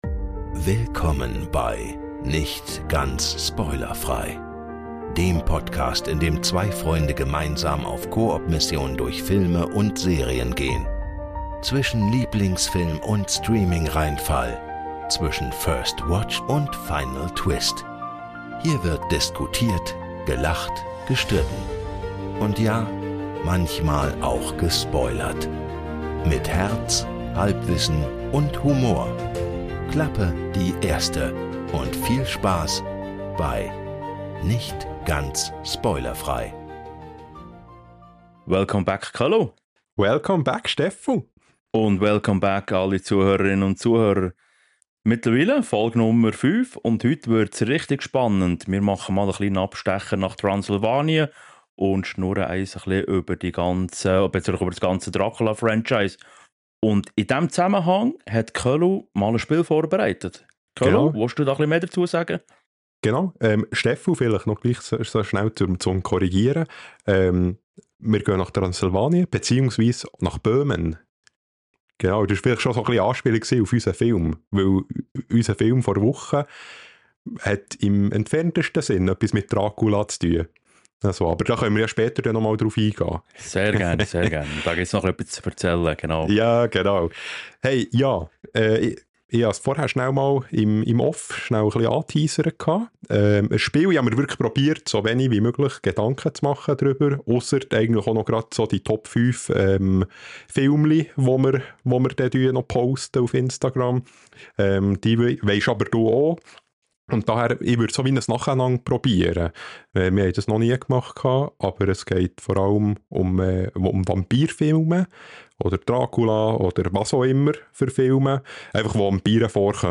Eine Folge voller Filmgeschichte, Streitgespräch und Biss.